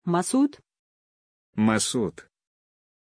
Aussprache von Masud
pronunciation-masud-ru.mp3